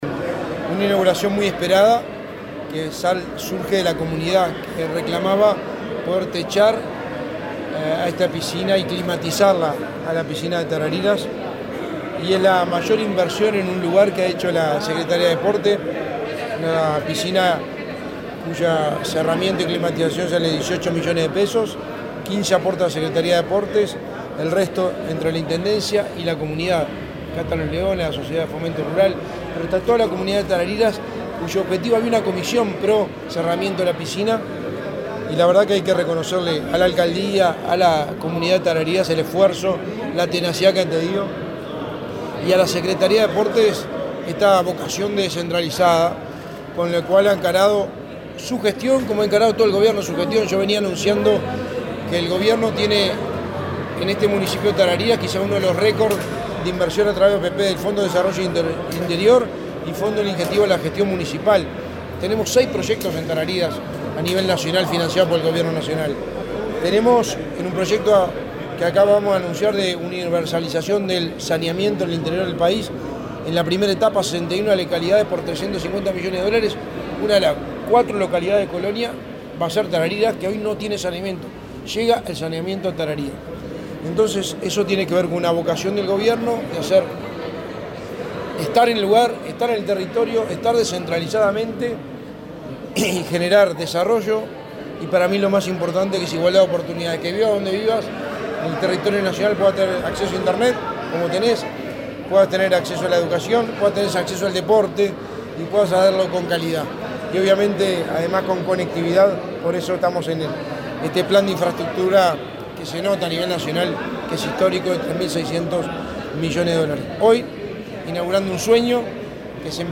Entrevista al secretario de Presidencia, Álvaro Delgado
Entrevista al secretario de Presidencia, Álvaro Delgado 06/12/2023 Compartir Facebook X Copiar enlace WhatsApp LinkedIn El secretario de Presidencia, Álvaro Delgado, dialogó con Comunicación en Colonia, luego de participar de la inauguración de las obras de techado y cerramiento de la piscina de la plaza de deportes de Tarariras.